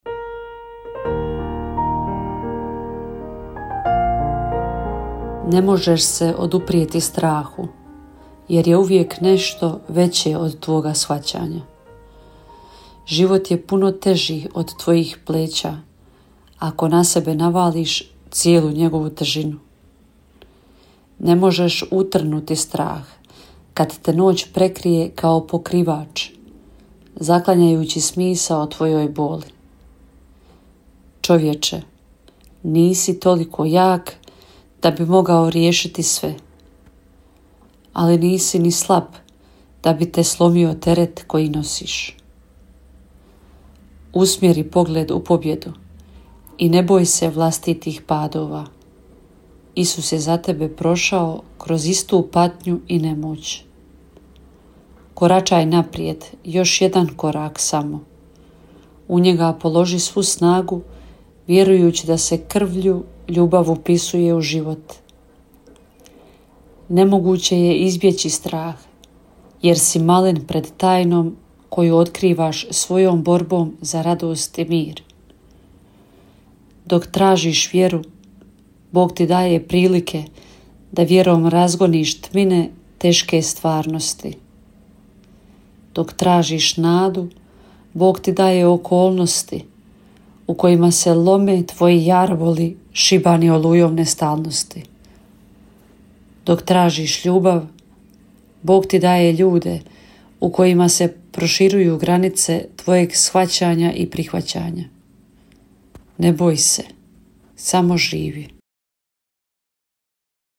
Kratku emisiju ‘Duhovni poticaj – Živo vrelo’ slušatelji Radiopostaje Mir Međugorje mogu čuti od ponedjeljka do subote u 3 sata i u 7:10. Emisije priređuju svećenici i časne sestre u tjednim ciklusima.